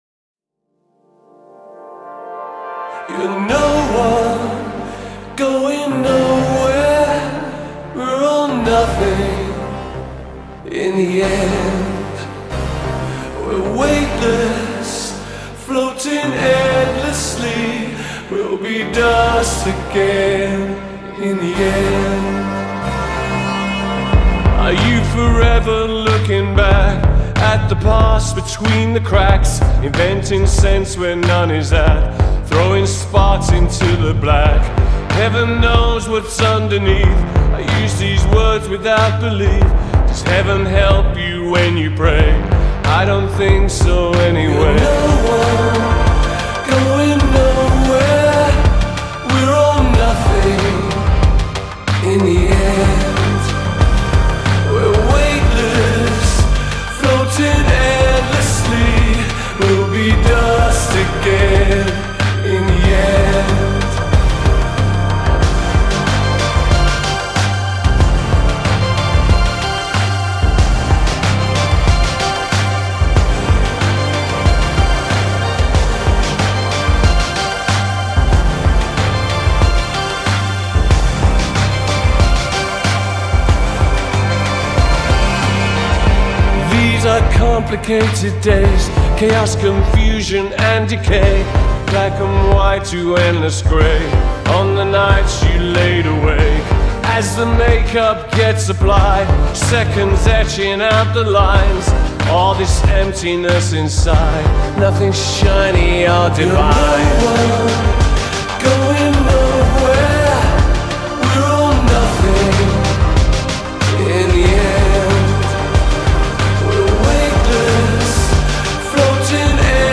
Every sound feels placed with precision.